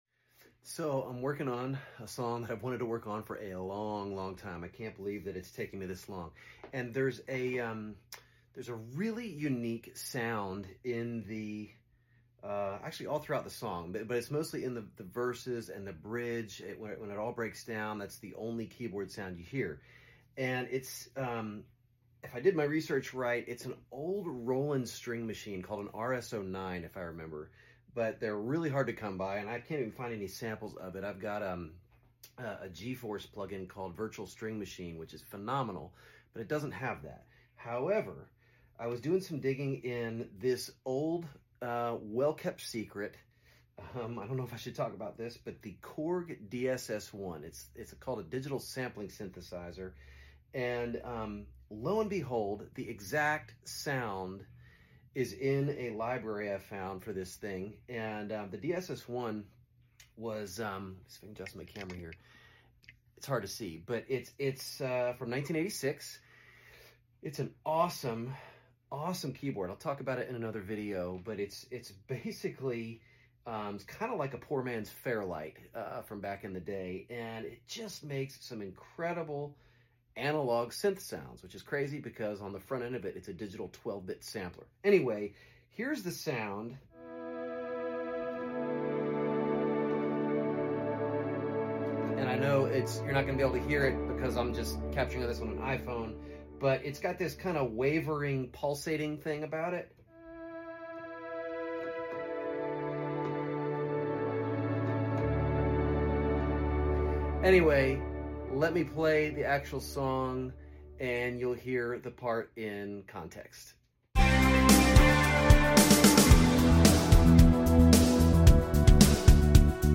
iconic synth sounds of the 80s